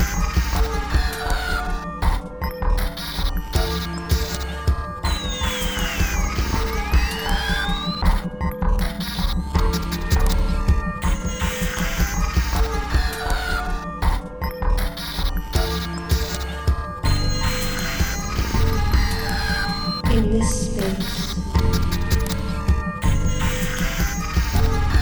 glitch music